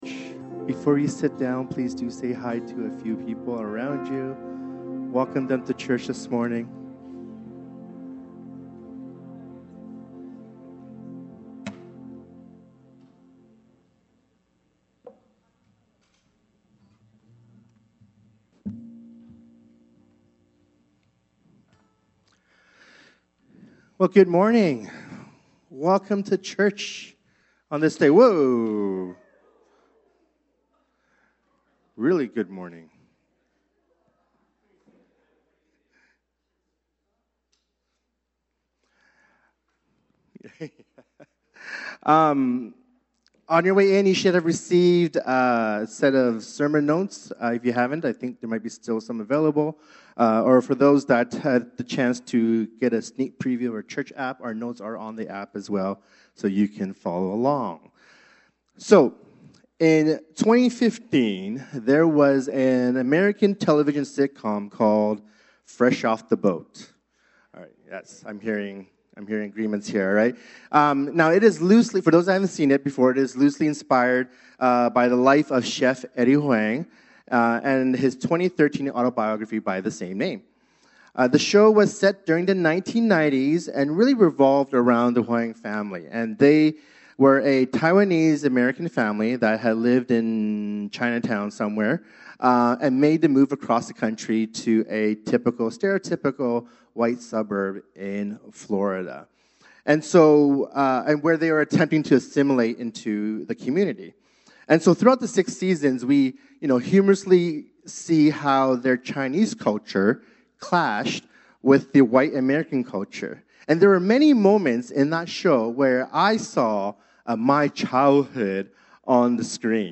Download Download Sermon Notes Seek First_Following-Jesus-Without-Dishonouring-Your-Parents_FILLED_Feb 22.26.pdf From this series One Church.